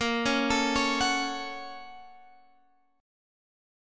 BbmM7#5 chord